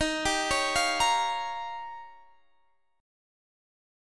Listen to D#9 strummed